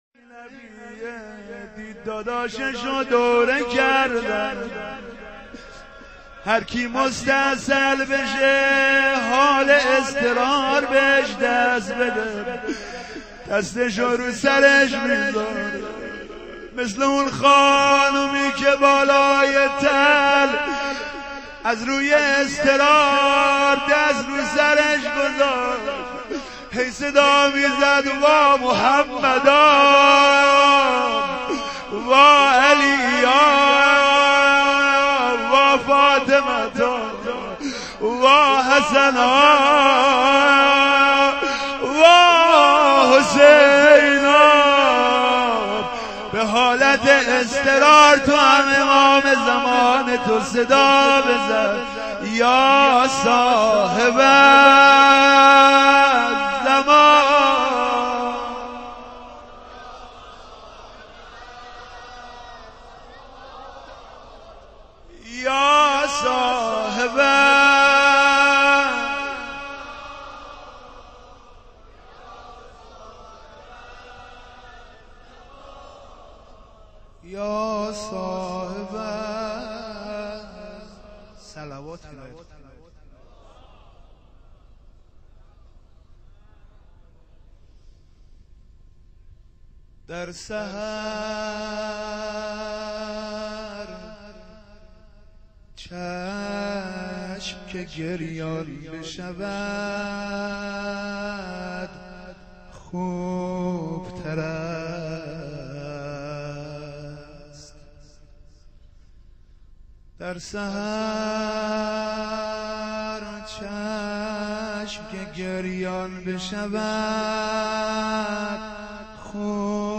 روضه.wma